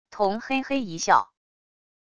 童嘿嘿一笑wav音频